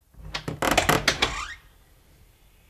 Squeaky Door Open